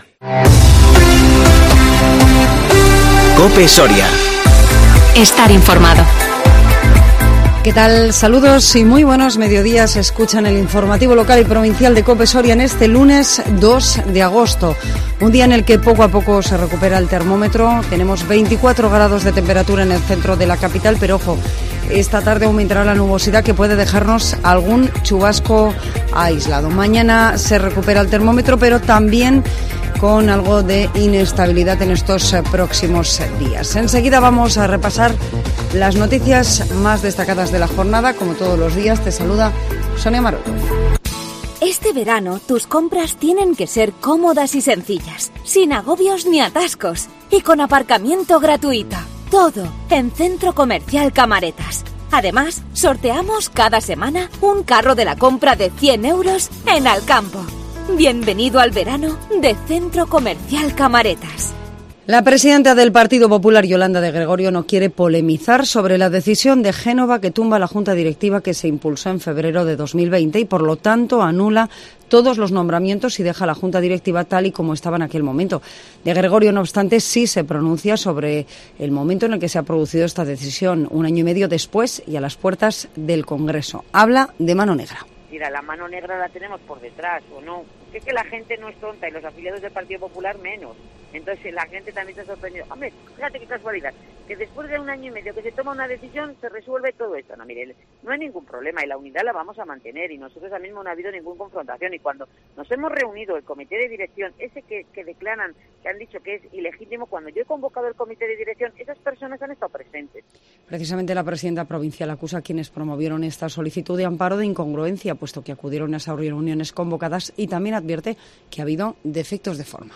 INFORMATIVO MEDIODÍA 2 AGOSTO 2021